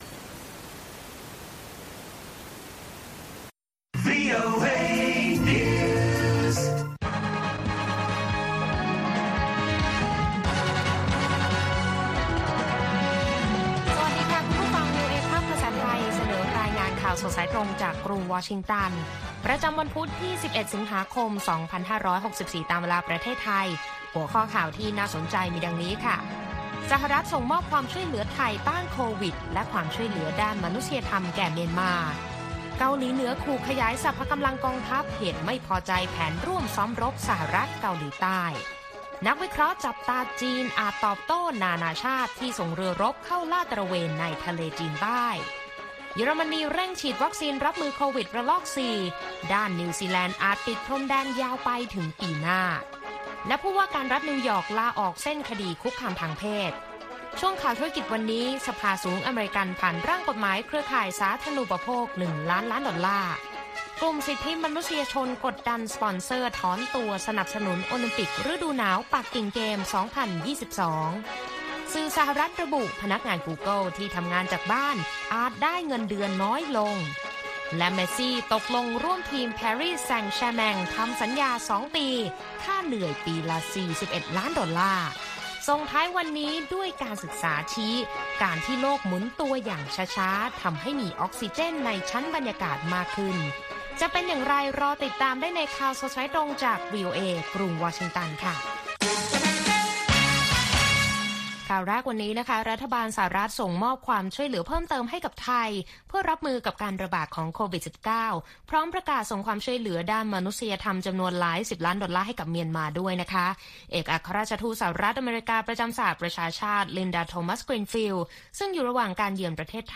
ข่าวสดสายตรงจากวีโอเอ ภาคภาษาไทยประจำวันพุธที่ 11 สิงหาคม 2564 ตามเวลาประเทศไทย